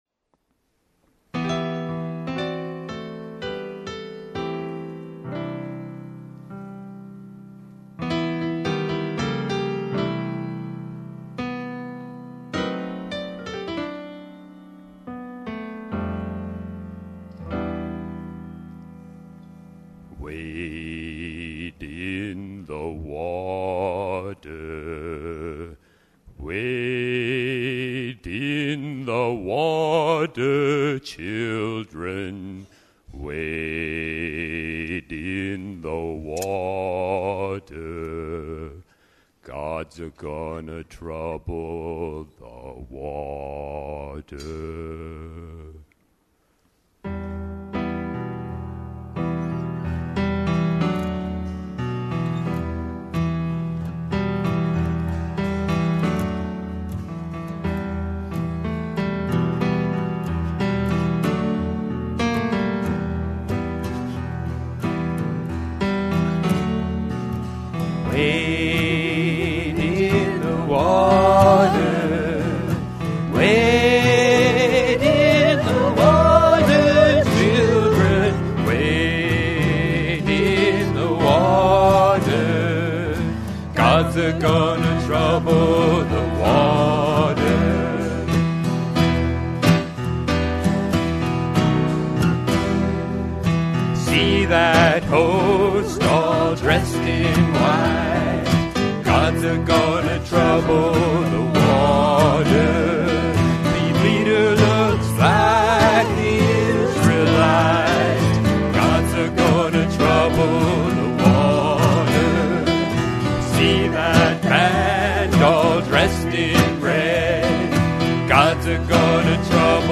Chapel Folk – friends having fun with music!
What we are: “a ‘garage band’ that gets together in the MWPC Chapel” (and in the local Food Pantry during the COVID pandemic.)